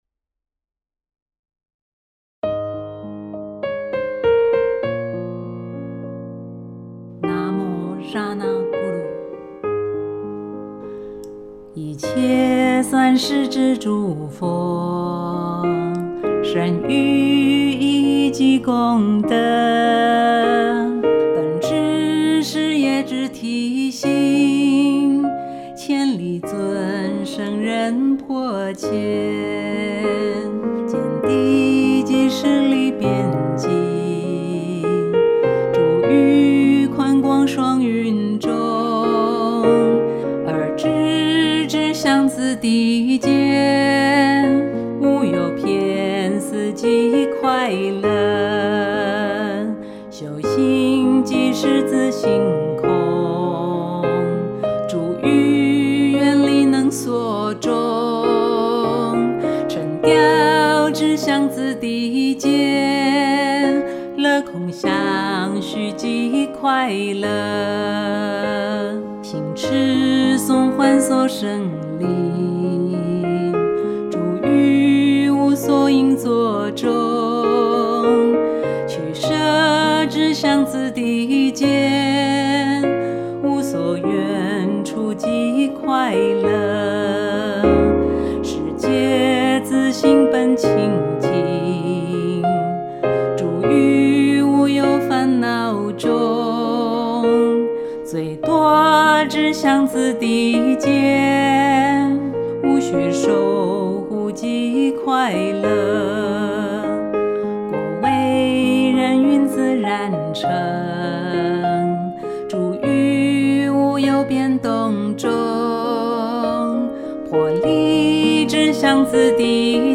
分類：金剛道歌
鋼琴配樂